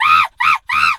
monkey_2_scream_01.wav